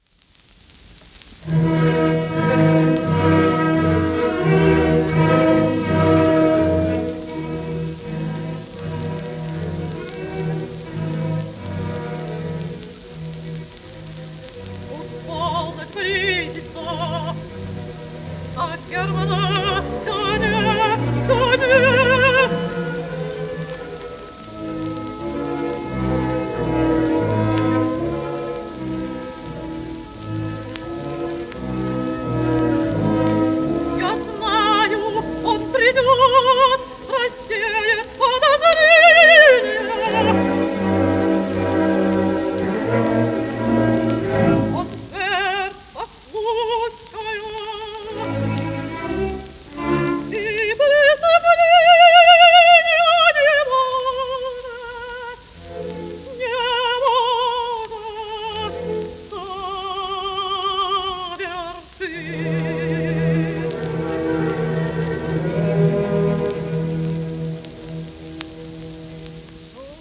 、オーケストラ
1927年録音